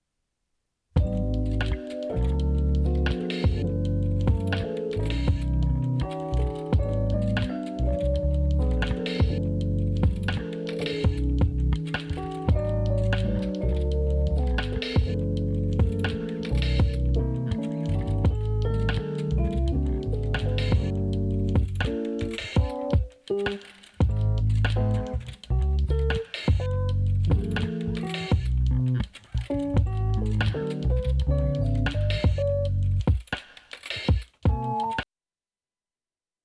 alrighty here i did some comparisons to test my own perception. definitely noticable difference, not horrible for me. the warble tends to be more noticeable on the older tapes to my ears. recorded from the headphone out on the WAR at max volume and the RCA out from the NAK without noise reduction since the WAR doesnt have it.
sample from a brand new cassette released within the past couple years